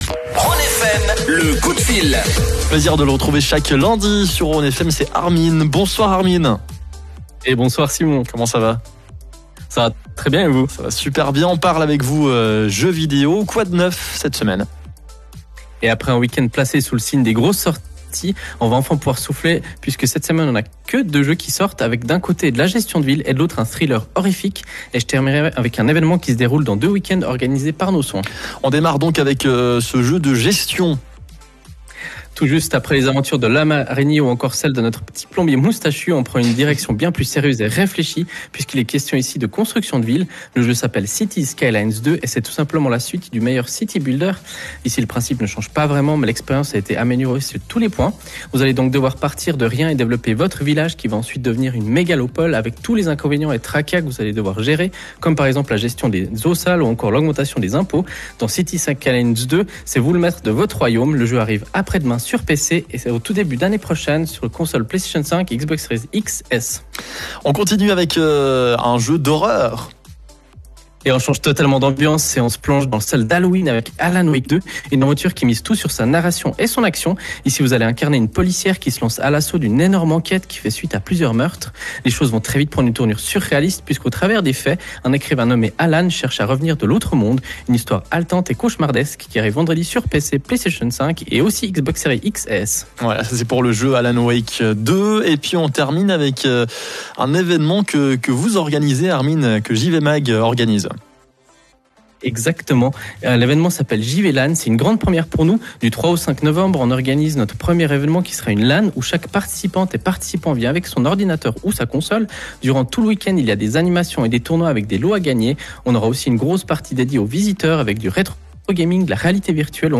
Pour cette nouvelle capsule « jeu vidéo », que nous avons la chance de proposer tous les lundis sur Rhône FM, on continu d’aborder cette période pleine de sorties.